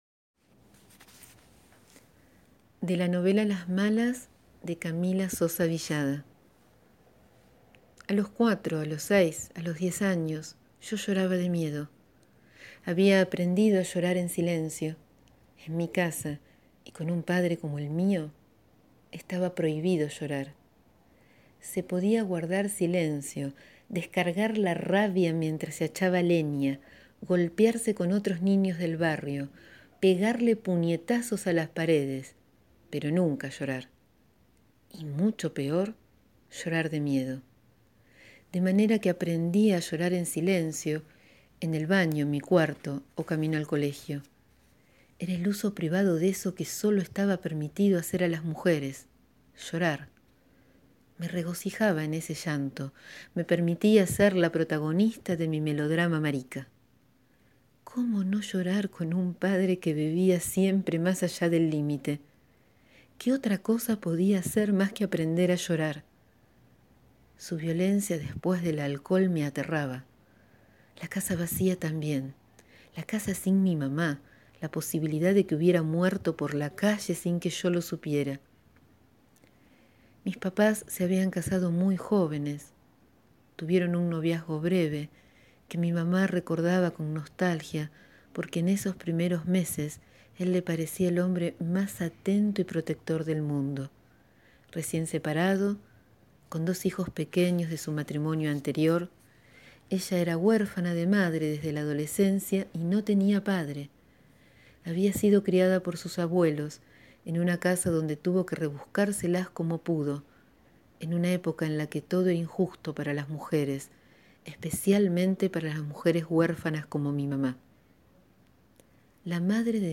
Hoy leo párrafos de la novela «Las malas» de Camila Sosa Villada (1982)